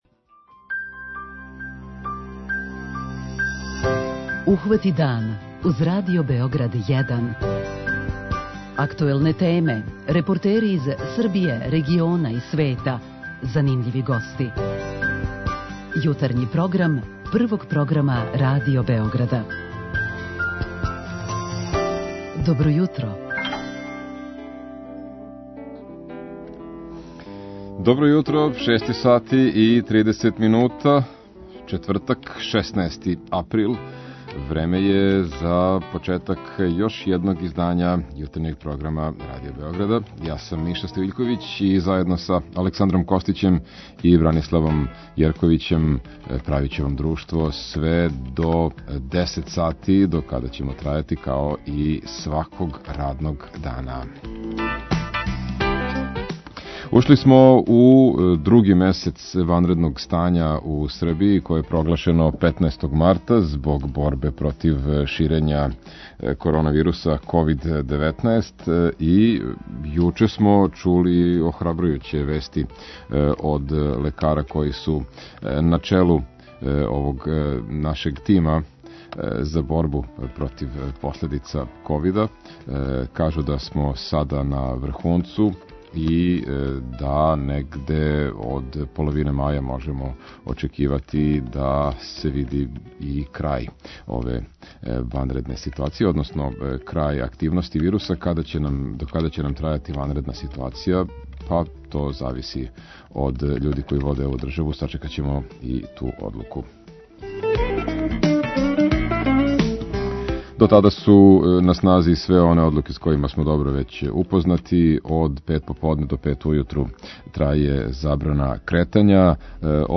Као и сваког јутра, припремили смо занимљив колажни програм у жељи да вас што боље информишемо о свим аспектима живота. Чућемо како се одвија сетва током ванредног стања и какве су нам залихе брашна, кукуруза и других важних намирница.